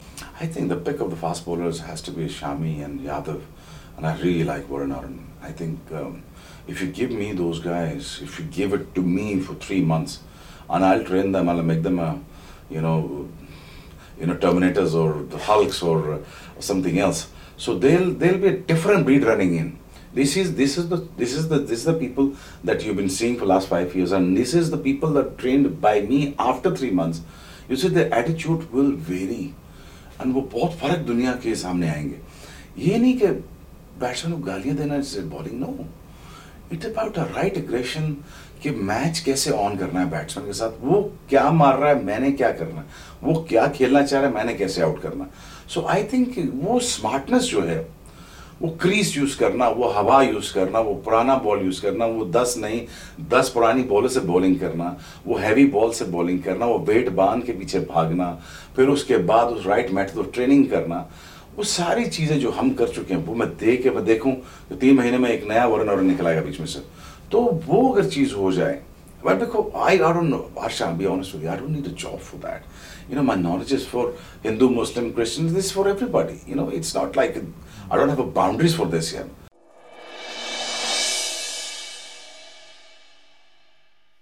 Shoaib Akhtar talks about his favourite Indian fast bowlers.